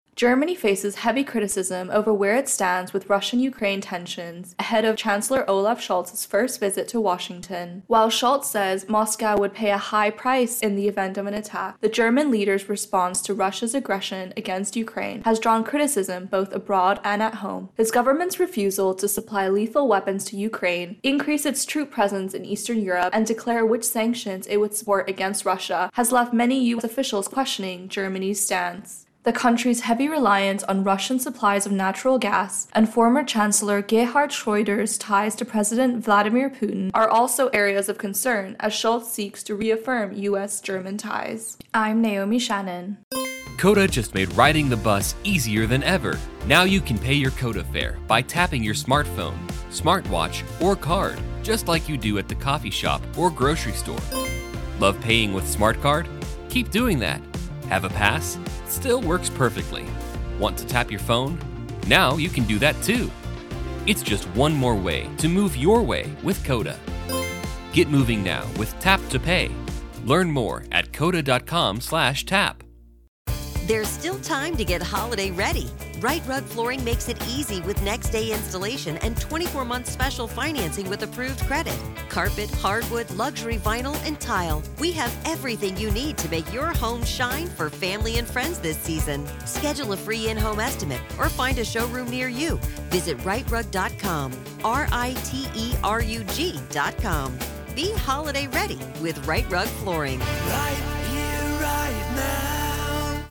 Intro and voicer on EU-Germany-US